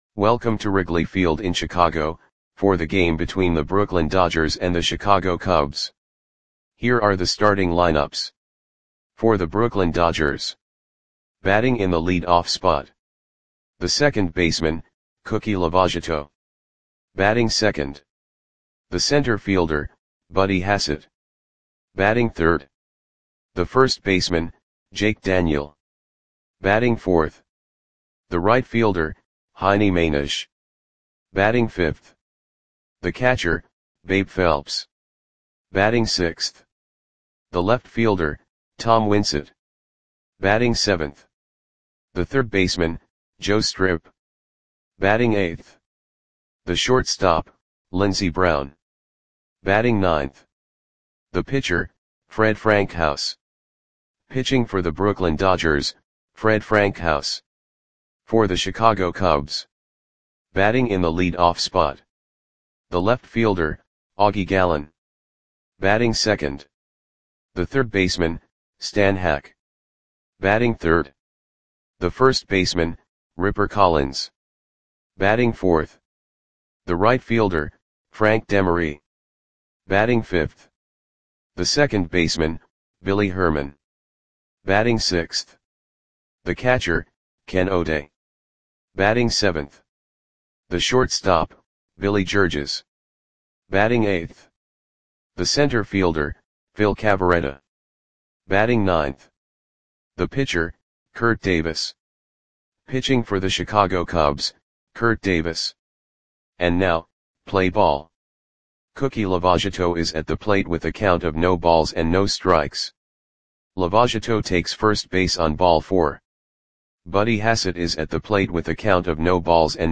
Audio Play-by-Play for Chicago Cubs on July 29, 1937
Click the button below to listen to the audio play-by-play.